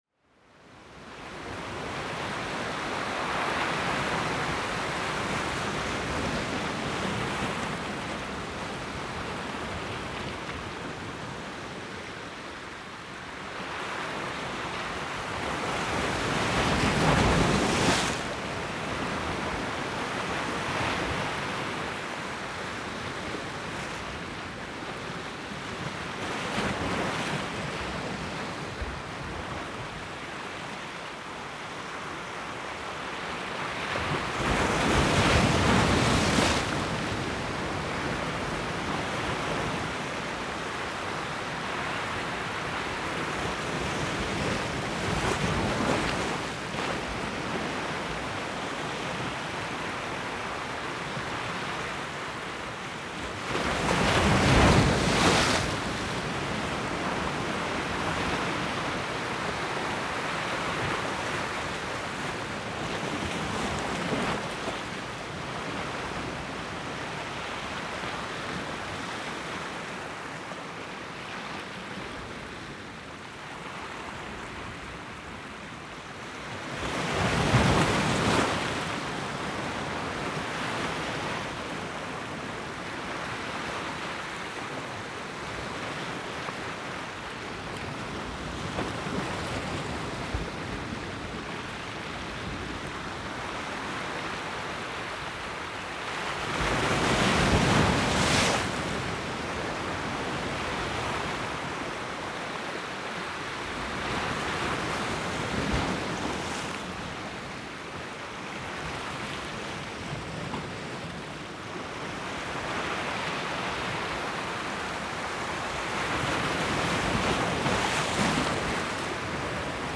Waves breaking on a rock | Matamec, Cote-Nord
The waves of the Saint Lawrence River breaking on a big rock, on a beach in Cote-Nord.
Between Moisie and Matamec rivers, Cote-Nord, QC, 50°16’54.0″N 65°58’21.5″W. November 18, 2017. 1:45PM.
Montage of 5 perspectives : Perspective 1 at 00:00min.
ae4cd-vagues-se-brisant-sur-un-rocher-_-matamec-cote-nord.m4a